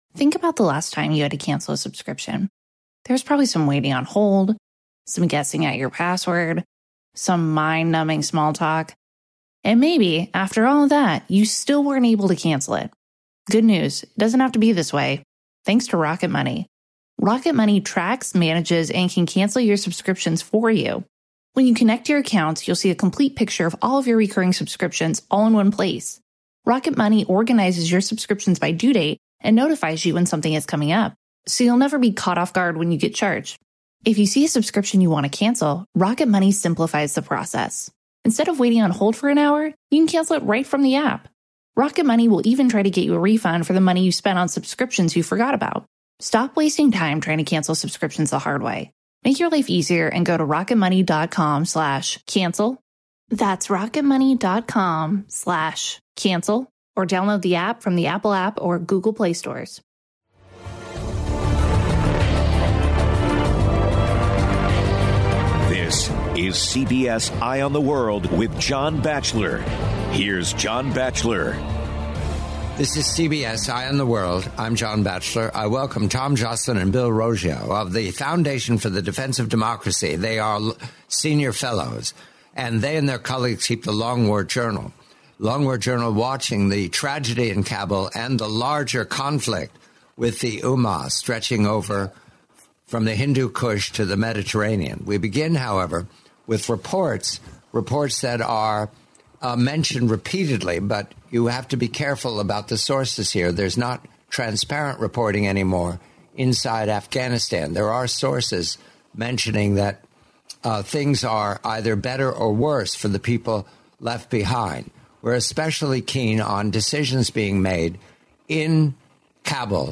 the complete, forty-minute interview